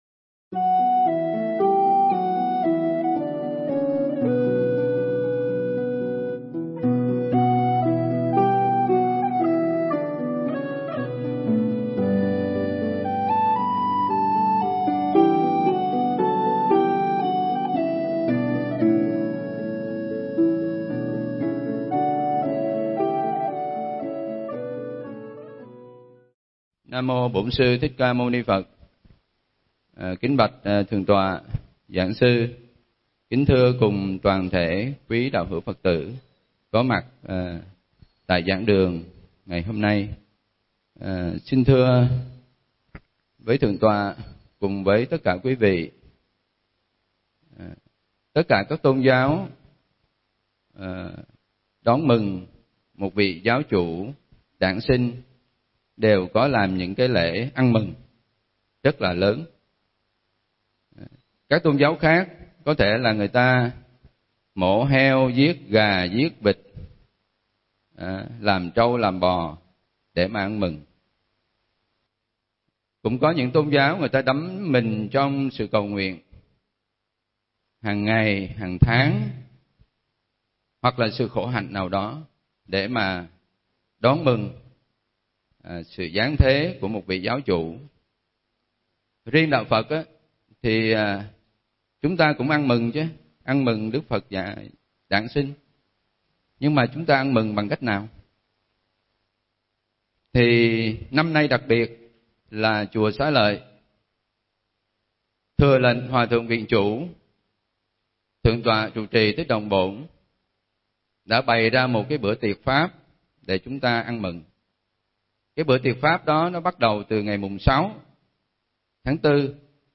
Nghe Mp3 thuyết pháp Tưởng Niệm Sự Xuất Hiện Của Đức Phật